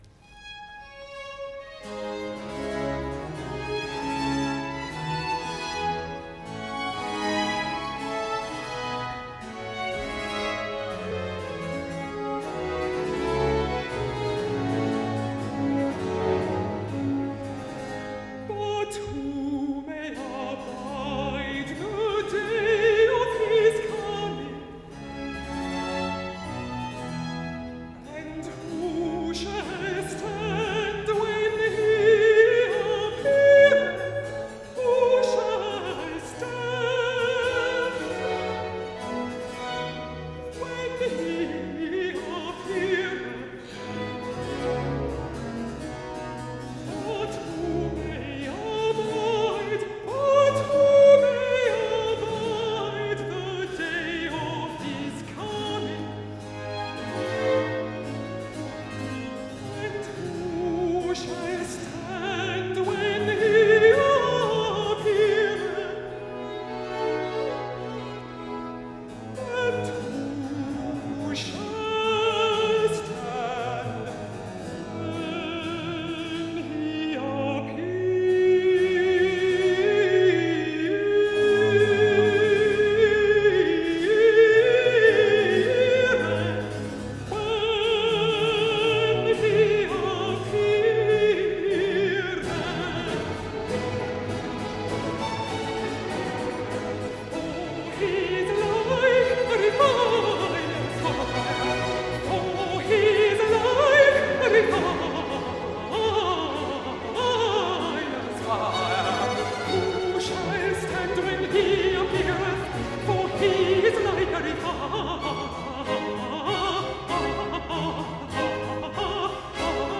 Aria-alto